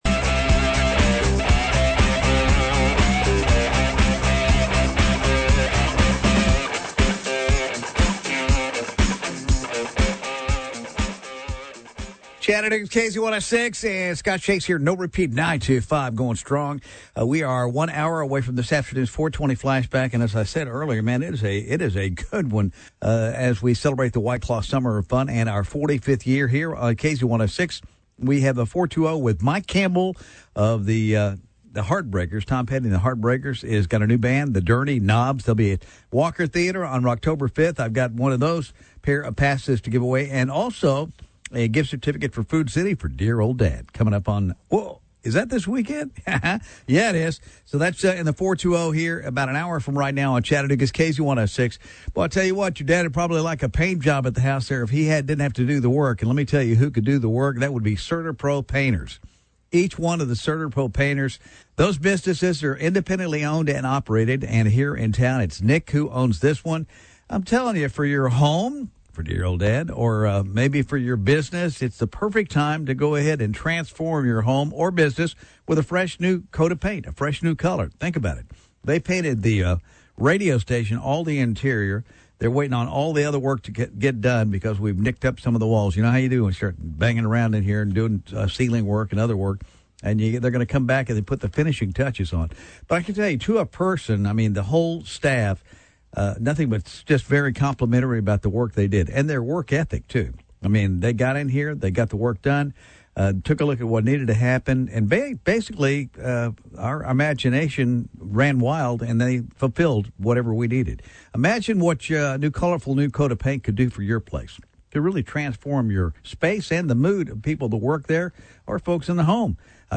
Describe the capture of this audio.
Radio Spots on KZ 106